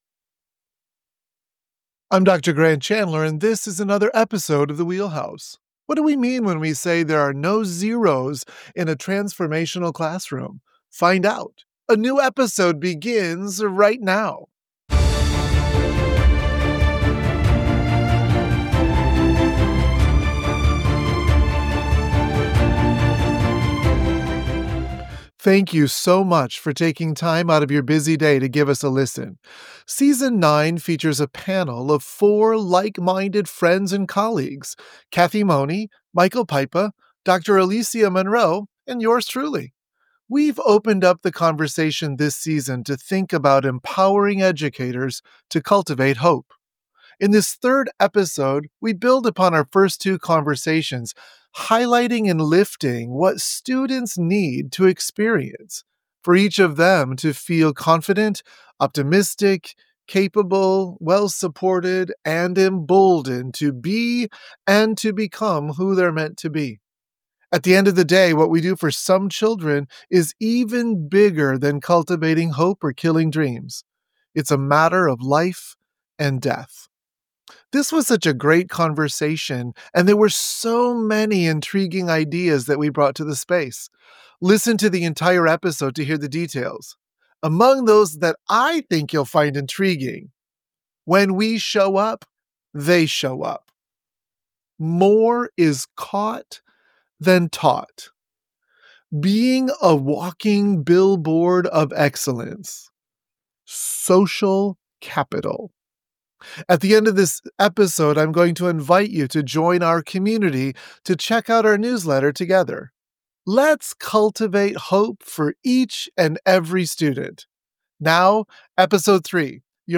We explore the disparity between educators' intentions and the actual experiences of students, emphasizing the necessity of relational over transactional interactions in educational settings. Our panel engages in profound discussions that highlight the imperative of creating safe spaces for courageous conversations, ultimately bridging the gap between intention and impact.